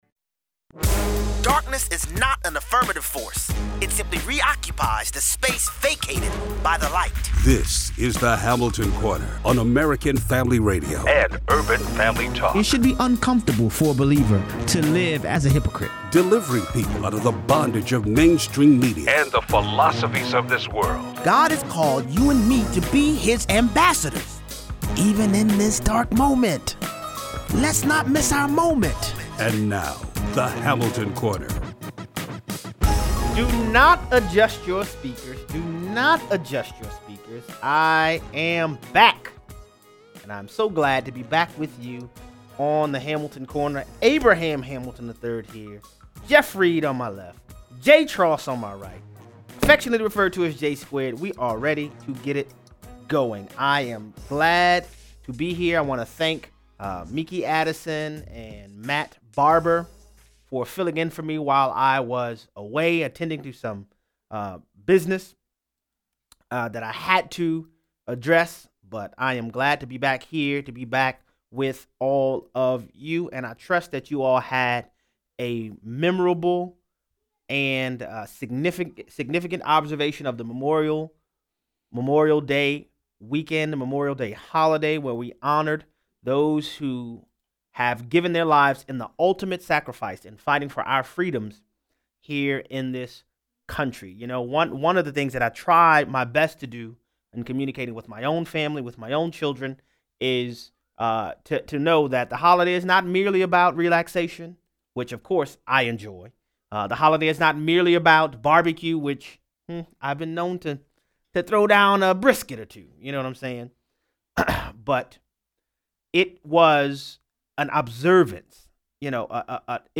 Abraham’s commitment to discipling his offspring is one of the reasons God invited him to more revelation. 0:23 - 0:40: Scientists discover that DNA bar-codes reveal that all life came into existence at the same time. The Obamas sign a $50 million contract with Netflix, but don’t worry their programming won’t be politically biased… riiight!!! 0:43 - 0:60: Ireland votes to approve abortion. Callers weigh in.